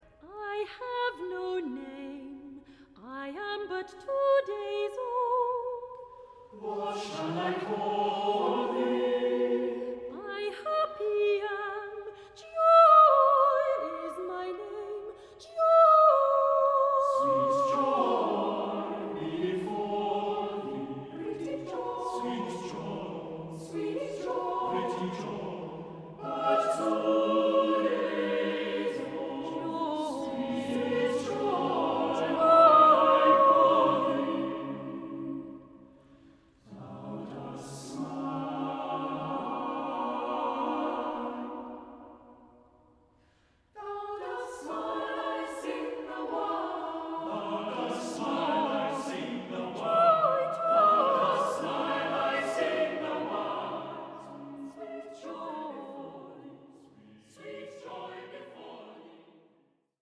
Choir & Baroque Orchestra